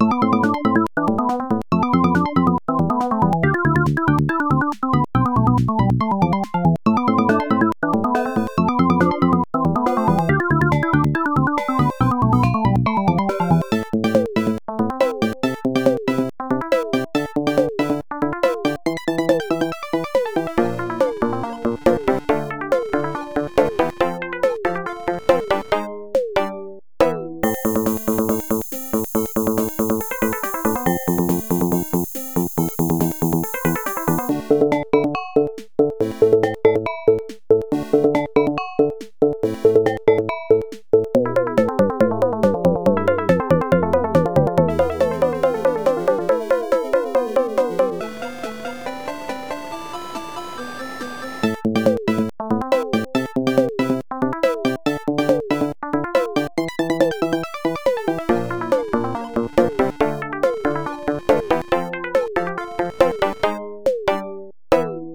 Not quite a chiptune, but very electronic in any case. Loopable.